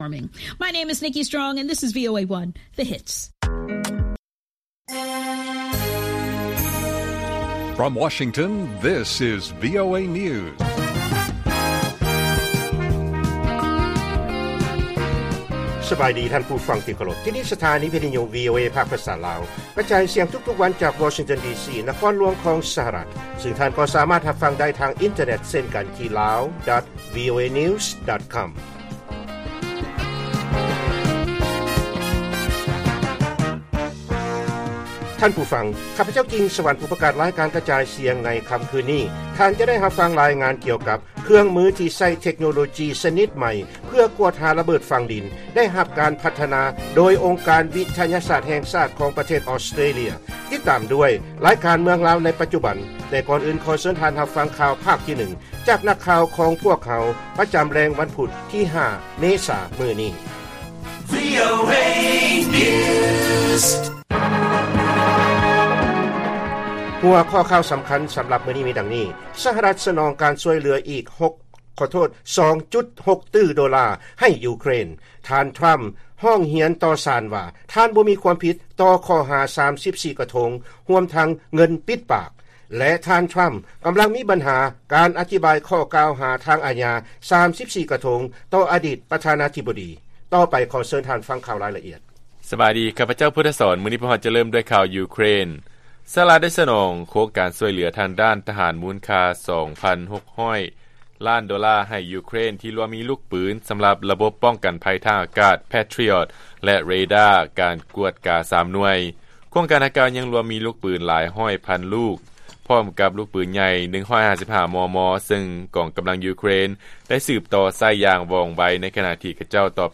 ລາຍການກະຈາຍສຽງຂອງວີໂອເອ ລາວ: ສະຫະລັດ ສະໜອງການຊ່ວຍເຫຼືອອີກ 2.6 ຕື້ໂດລາ ໃຫ້ ຢູເຄຣນ